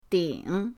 ding3.mp3